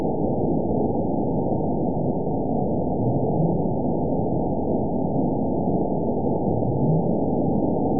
event 912418 date 03/26/22 time 10:03:27 GMT (3 years, 1 month ago) score 8.92 location TSS-AB01 detected by nrw target species NRW annotations +NRW Spectrogram: Frequency (kHz) vs. Time (s) audio not available .wav